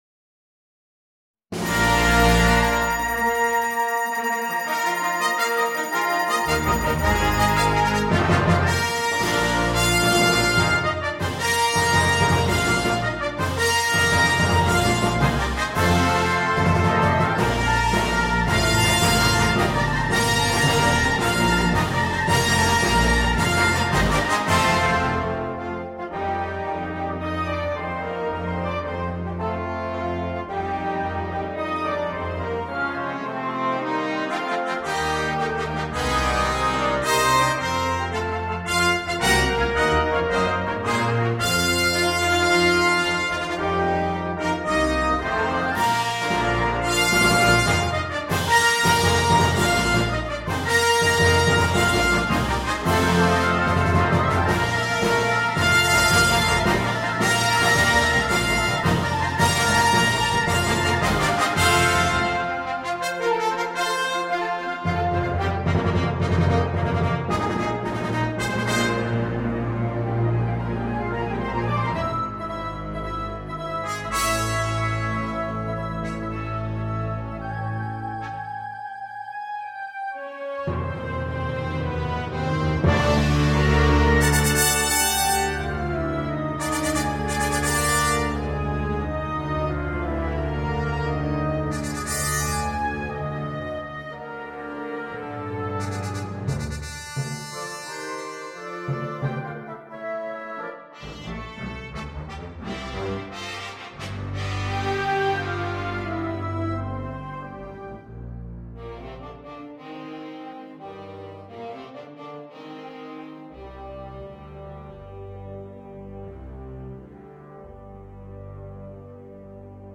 для духового оркестра.